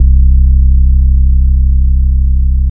Bass (CleanSine) - Loop.wav